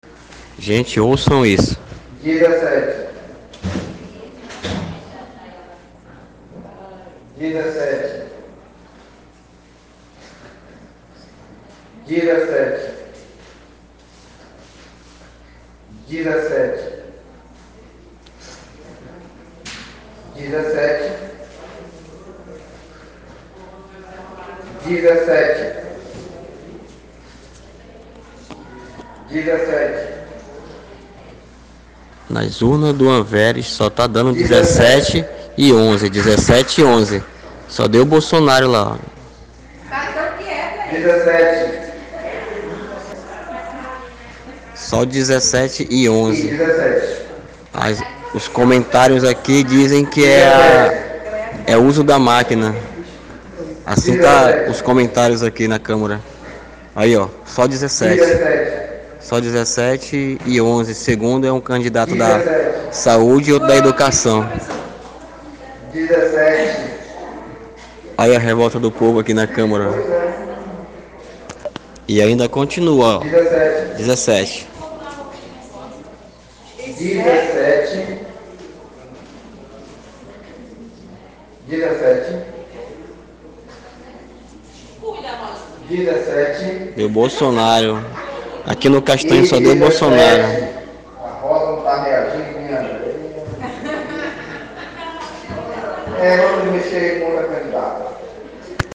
Careiro/AM – As eleições para escolha dos Conselheiros Tutelar do Careiro 2019 foi realizada sem grandes problemas, mas aconteceu algo inédito uma reviravolta no final da apuração dos votos que foi realizada na Câmara Municipal do Careiro e terminou na madrugada de hoje, acompanhe a seguir a reviravolta.
da apuração dos votos, urnas do Anveres: